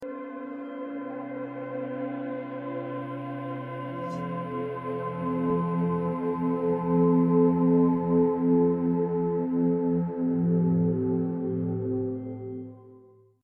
Close your eyes, take a breath, and let the 432Hz frequency calm your mind.